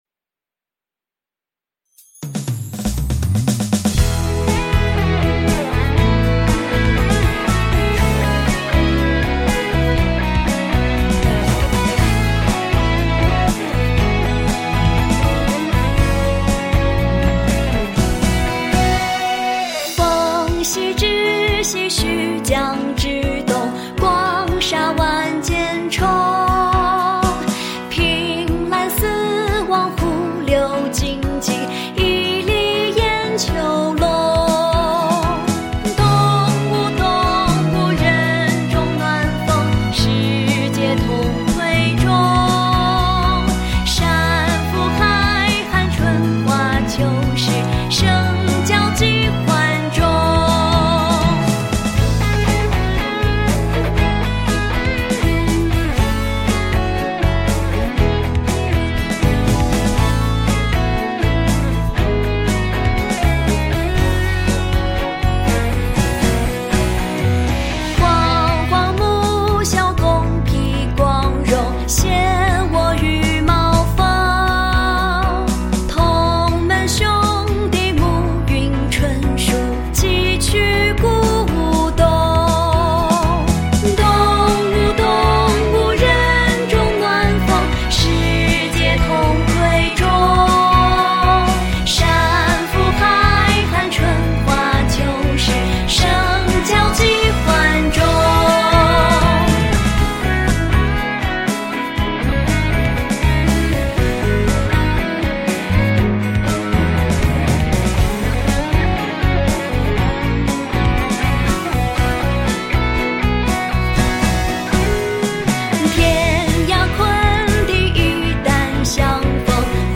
当熟悉的词句伴着轻快旋律在耳畔响起